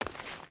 SFX / Footsteps / Stone / Stone Run 1.ogg
Stone Run 1.ogg